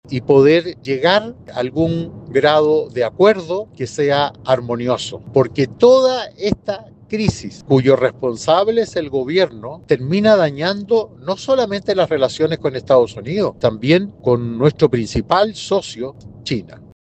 Pese a ello, el senador Iván Moreira (UDI) sostuvo que esta puede ser una oportunidad para estrechar lazos con Estados Unidos y abordar la controversia por el cable submarino chino, buscando algún tipo de consenso.